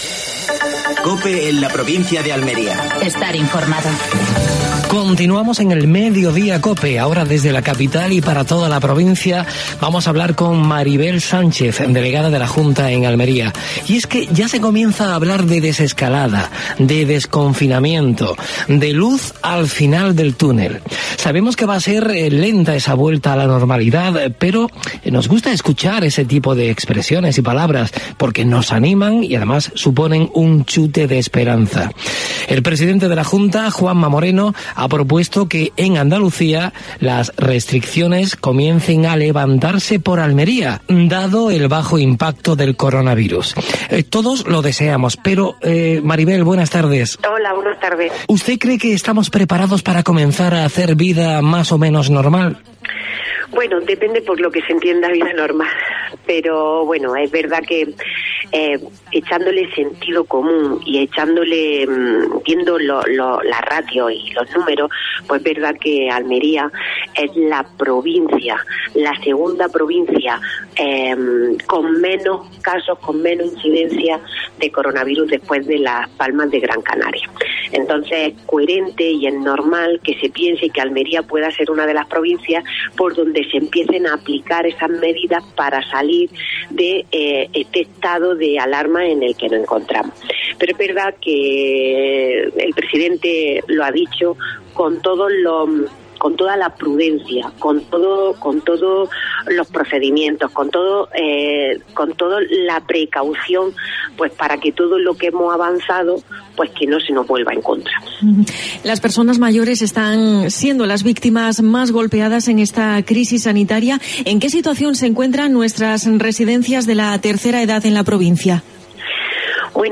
AUDIO: Actualidad en Almería. Entrevista a Maribel Sánchez (delegada del Gobierno de la Junta de Andalucía en Almería).